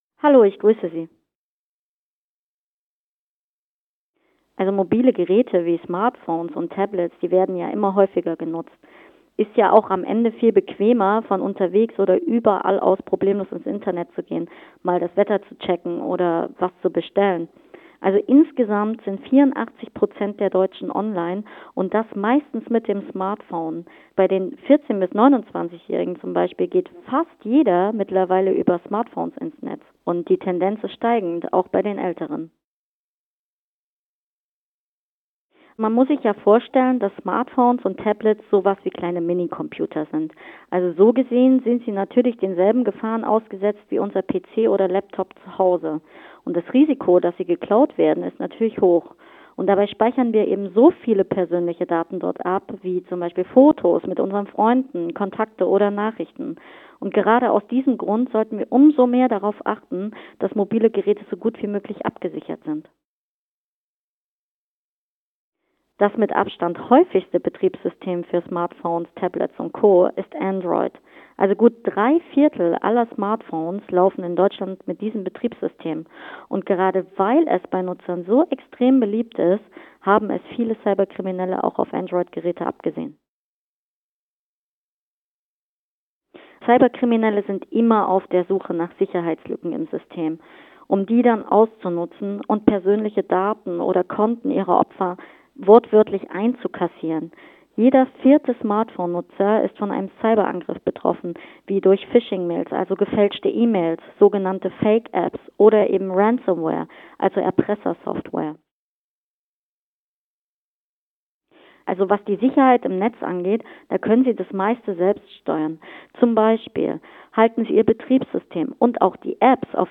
Interview: Sicherheitslücken! Tipps zum Schutz von Tablets und Smartphones.
Manuskript zum Interview